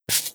GrassStep1.wav